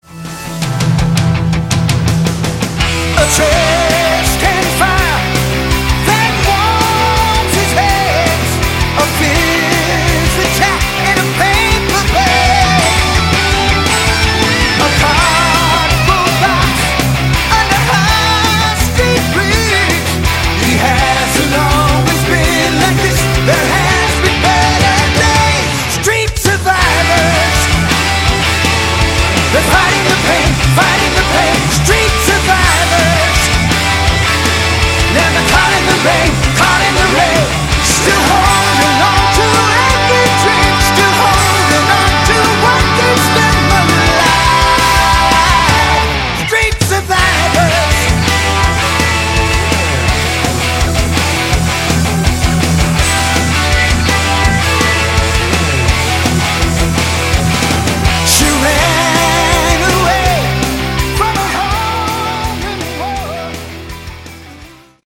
Category: AOR
lead vocals
lead and rhythm guitar, bass, keyboards, backing vocals
drums
Hammond B3, piano, analog synthesizers